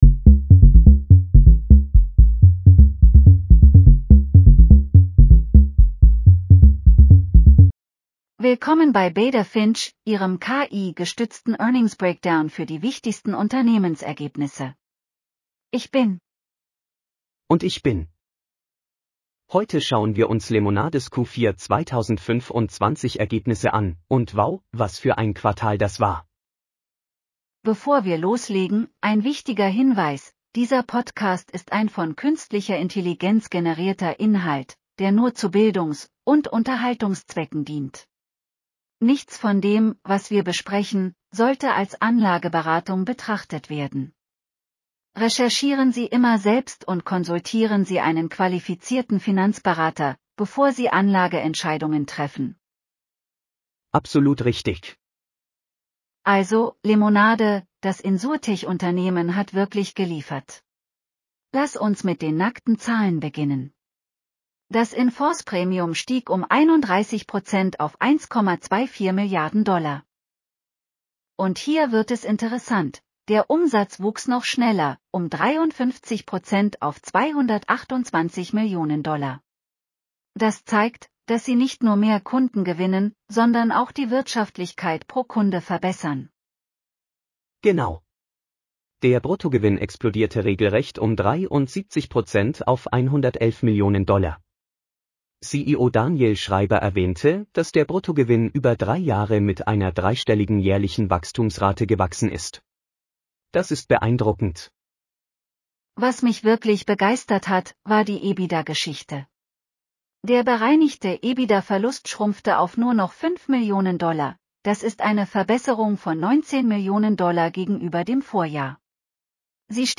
• AI-generated insights and analysis